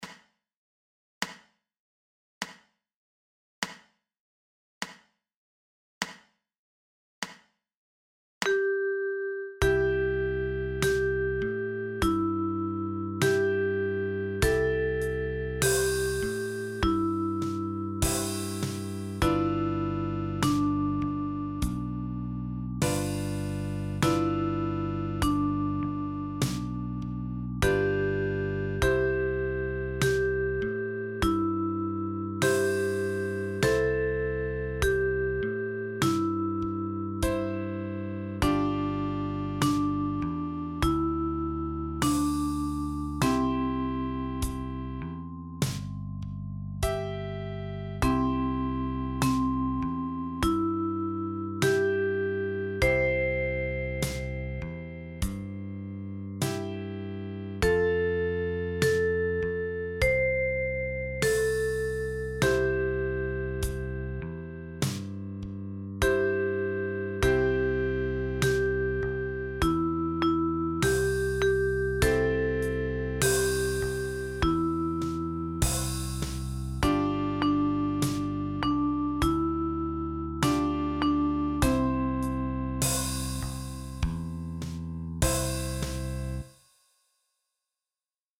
Für Sopran- oder Tenorblockflöte in barocker Griffweise.